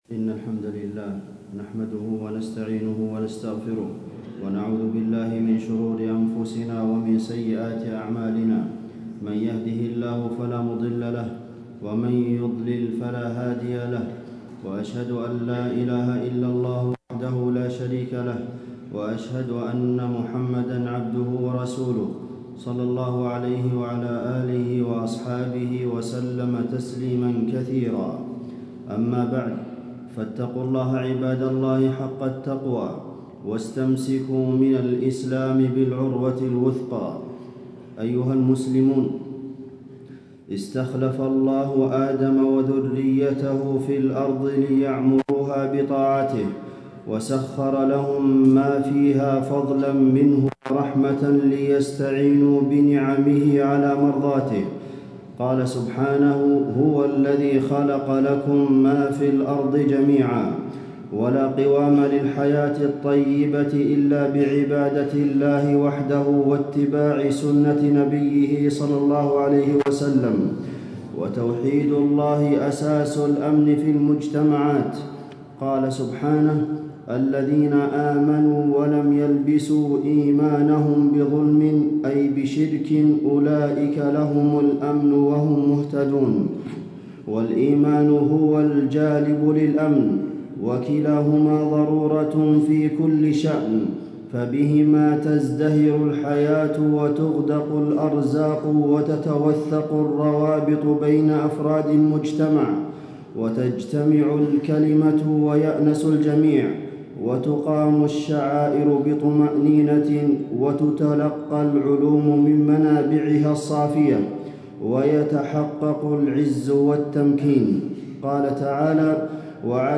تاريخ النشر ٢٨ جمادى الآخرة ١٤٣٦ هـ المكان: المسجد النبوي الشيخ: فضيلة الشيخ د. عبدالمحسن بن محمد القاسم فضيلة الشيخ د. عبدالمحسن بن محمد القاسم من فضائل الجهاد في سبيل الله